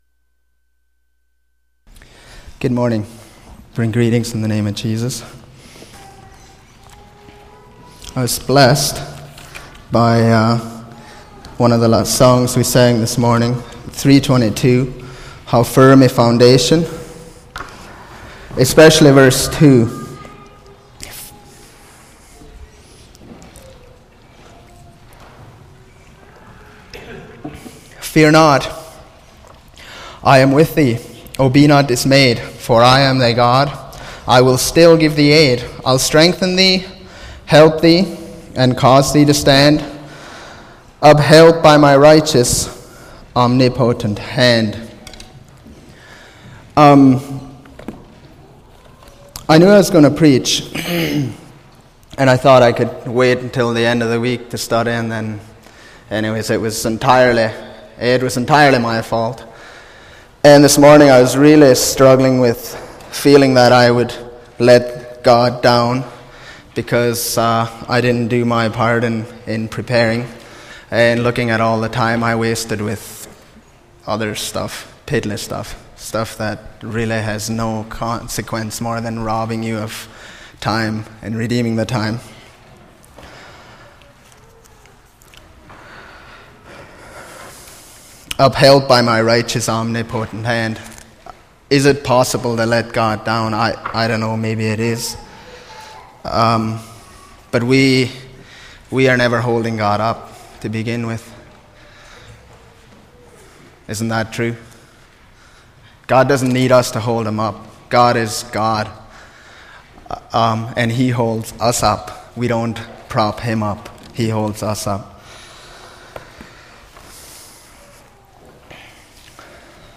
Series: Sunday Morning Sermon